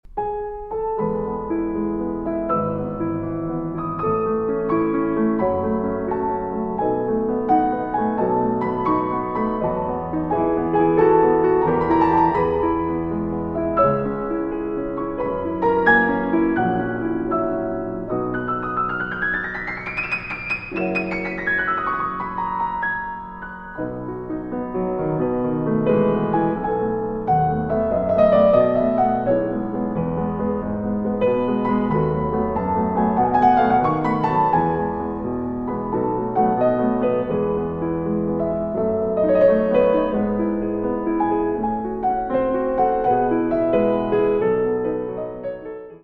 Andante espressivo (1:29)